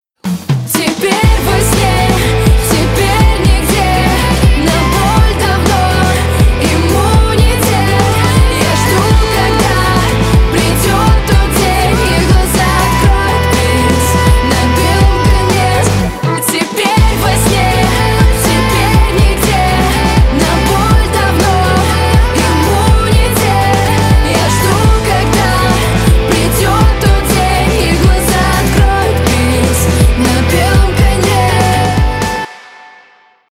Поп Музыка
громкие